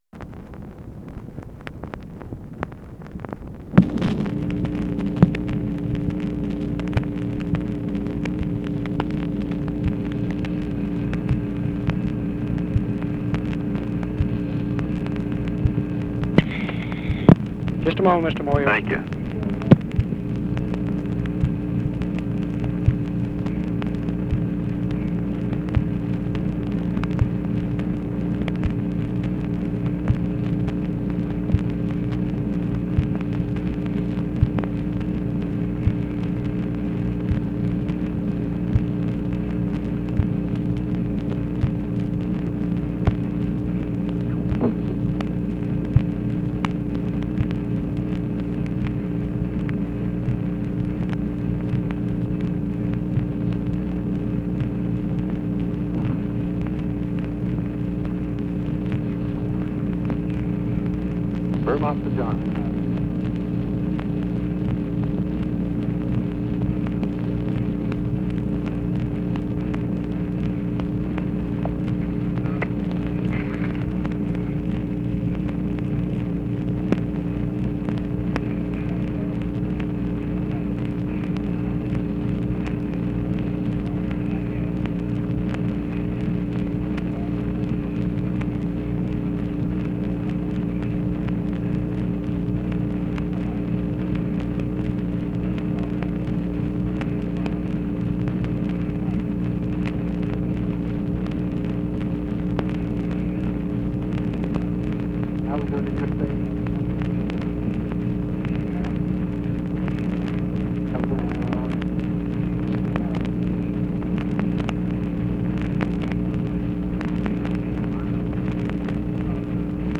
Conversation with BILL MOYERS, OFFICE CONVERSATION and SIGNAL CORPS OPERATOR, November 3, 1964
Secret White House Tapes